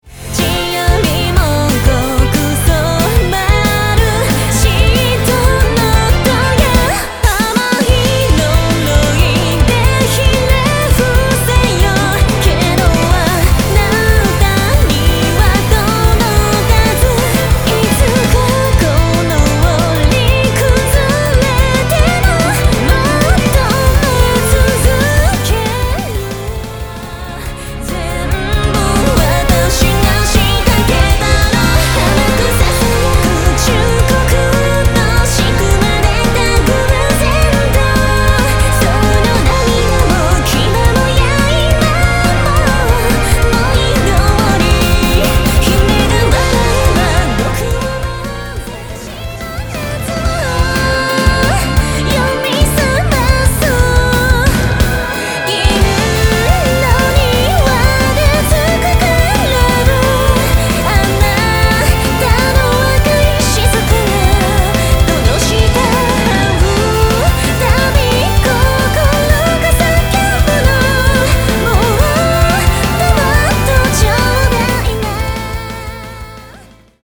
【耽美幻想シンフォニア】